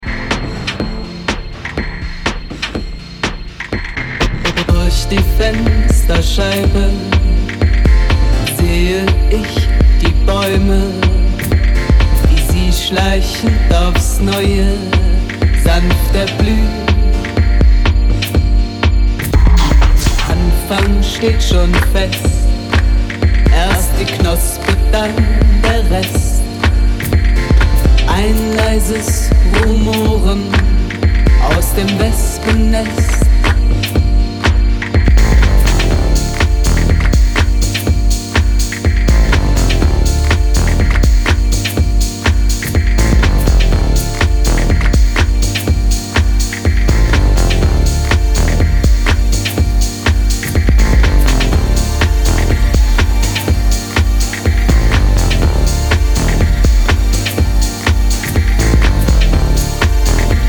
electronic sounds